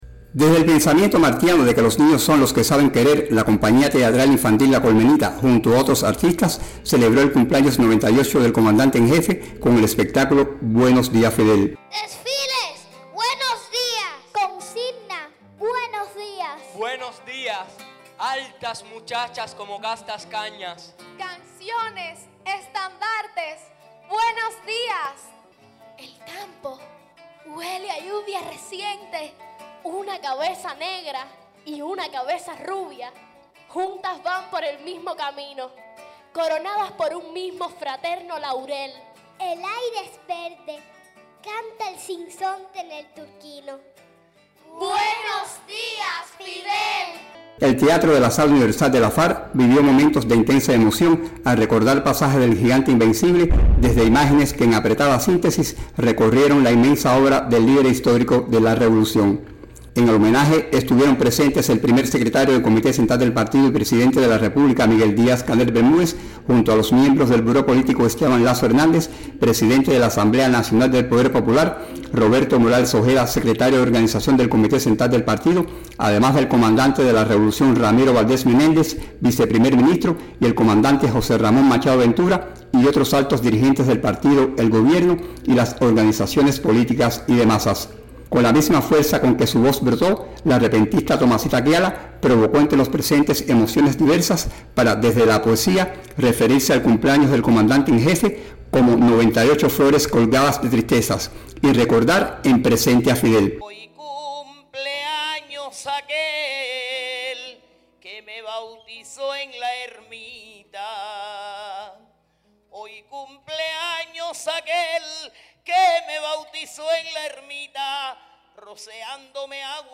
Por la inmensa obra que nos dejara para la continuidad de la Revolución, Fidel fue recordado vivo, durante una velada cultural que tuvo nuevamente como protagonista a la Compañía Infantil “La Colmenita”.